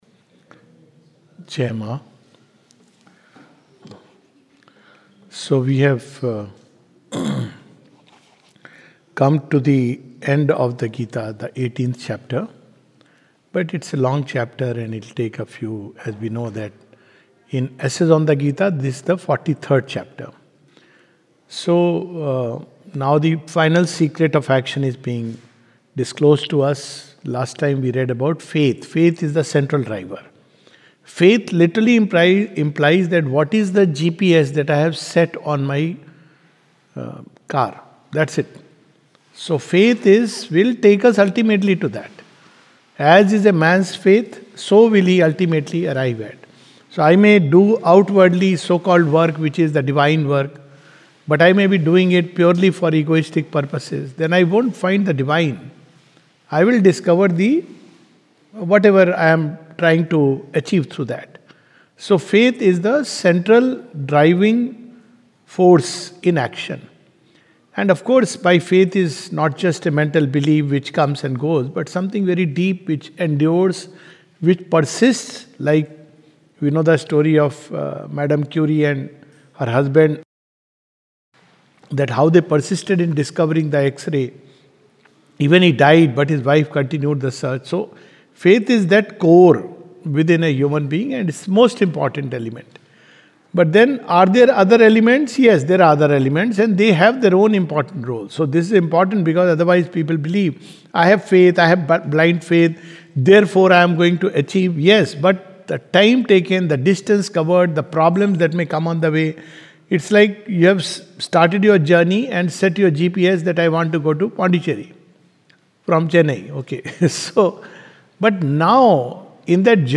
This is a summary of Chapter 19 of the Second Series of "Essays on the Gita" by Sri Aurobindo. A talk